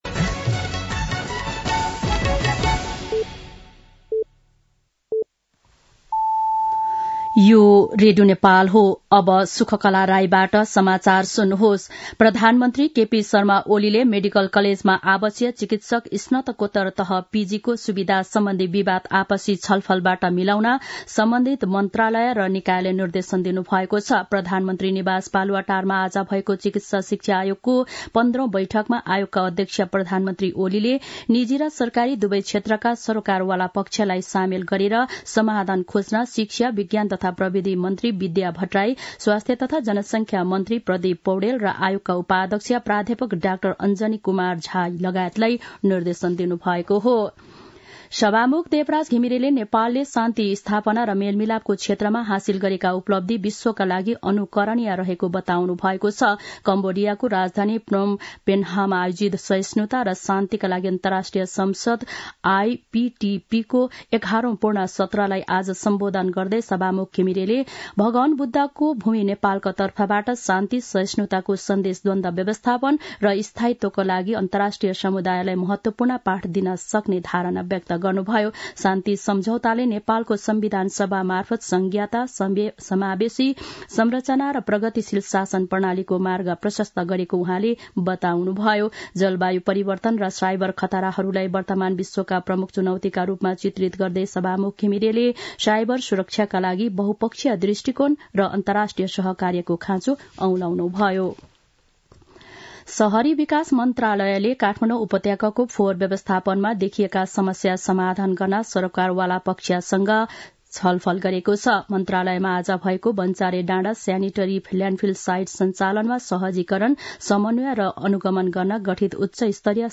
दिउँसो ४ बजेको नेपाली समाचार : १० मंसिर , २०८१
4-pm-Nepali-News-3.mp3